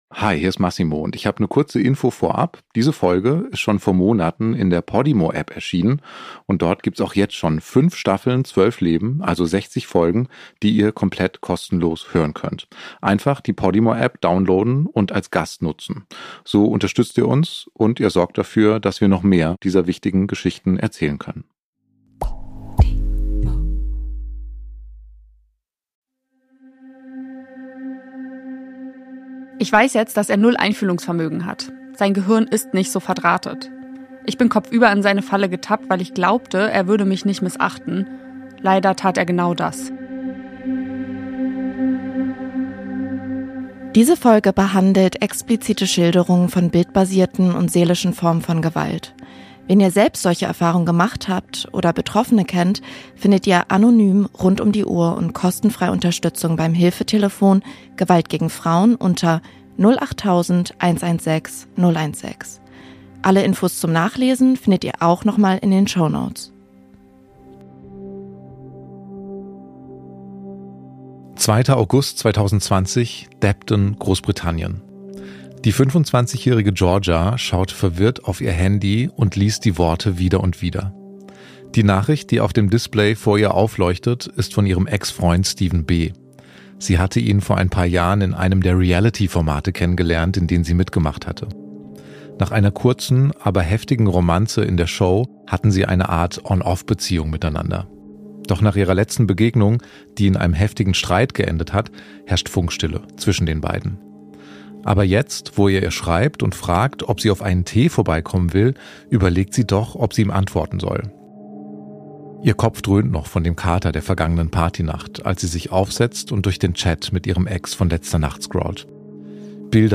Außerdem zitieren wir Georgia selbst aus ihrem Buch “Taking Back My Power”.